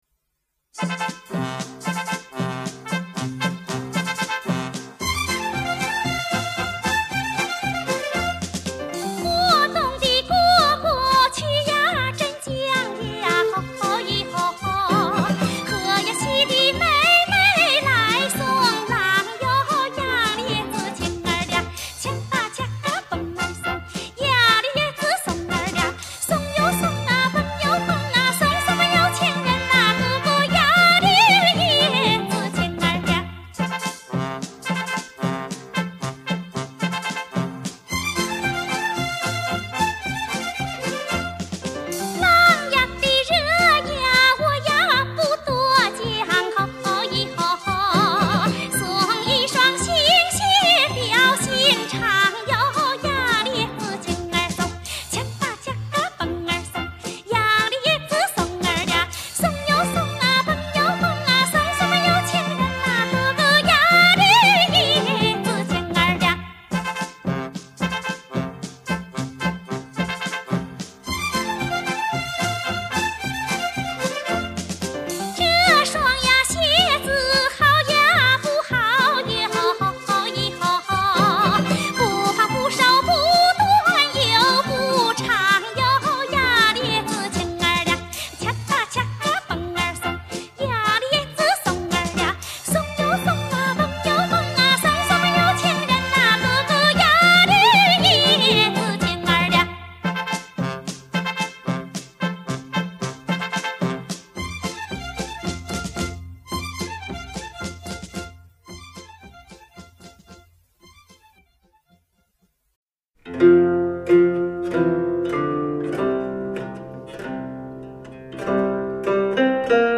江苏杨洲民歌
山西民歌